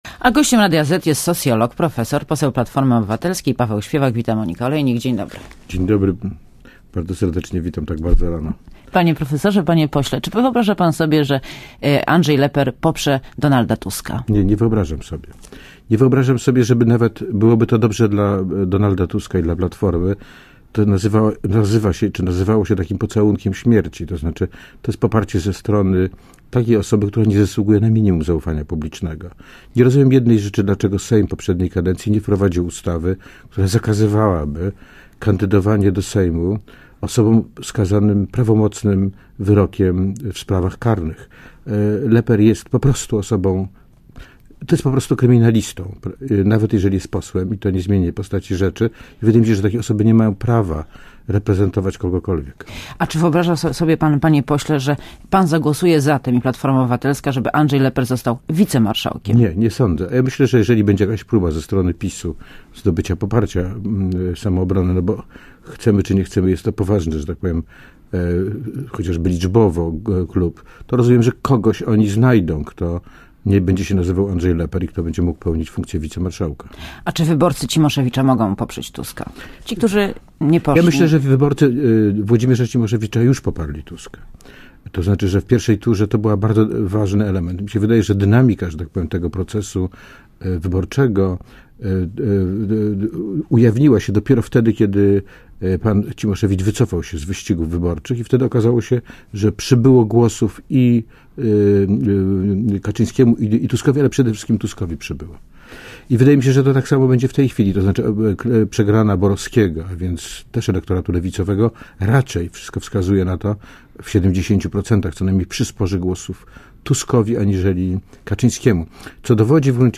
Posłuchaj wywiadu Gościem Radia ZET jest socjolog, profesor, poseł Platformy Obywatelskiej, Paweł Śpiewak.